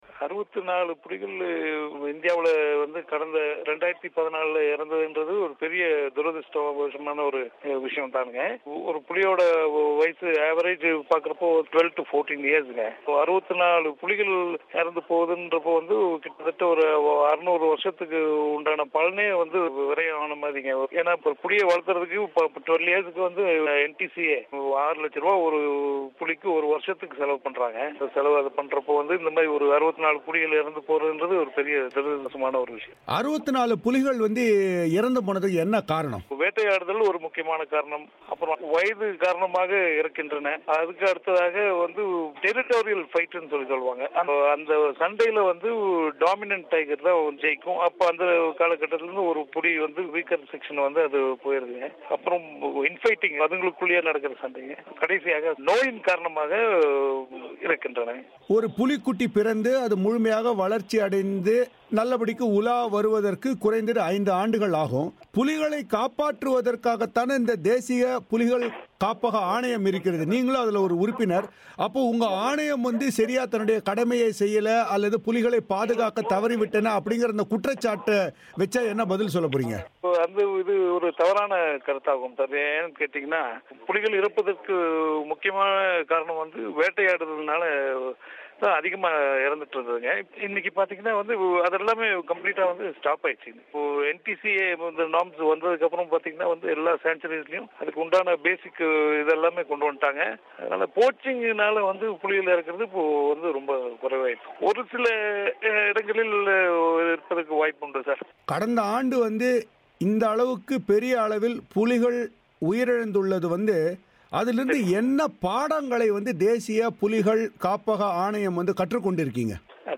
பேட்டி.